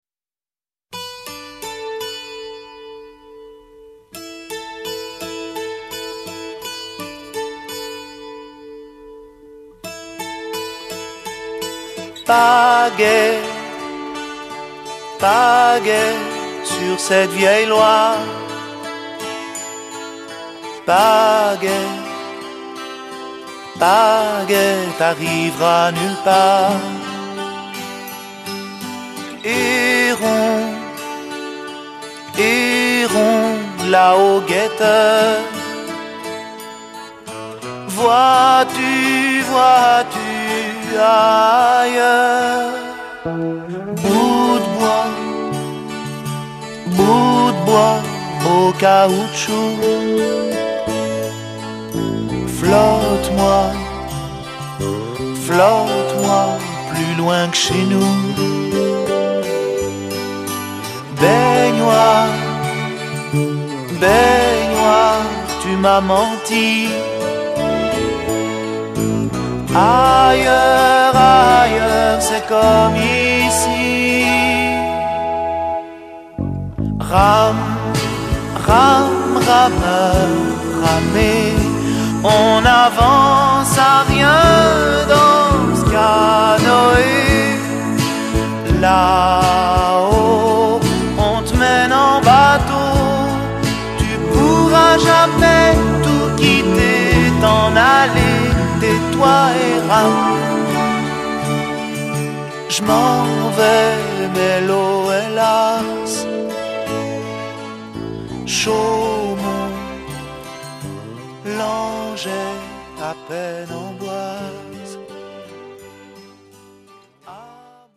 tonalités RÉ, SOL, DO et LA majeur